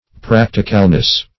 Practicalness \Prac"ti*cal*ness\, n.